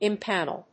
音節im・pan・el 発音記号・読み方
/ɪmpˈænl(米国英語)/